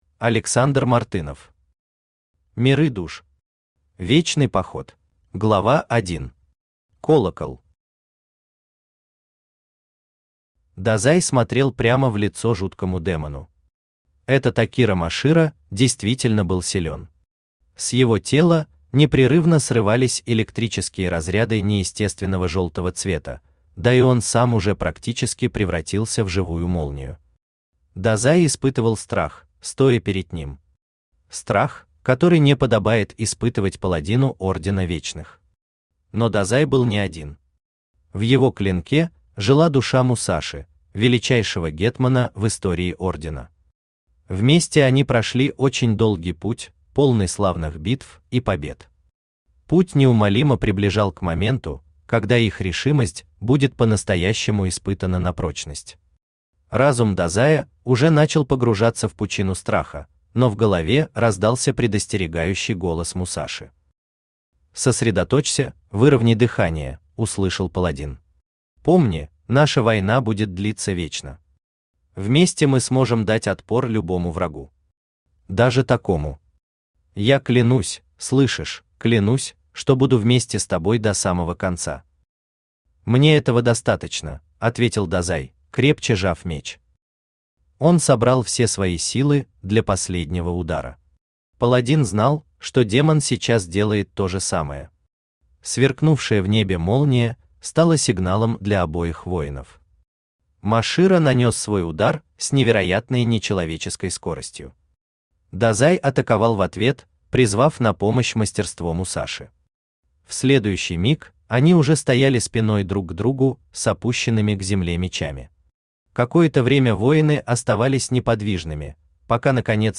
Вечный поход Автор Александр Мартынов Читает аудиокнигу Авточтец ЛитРес.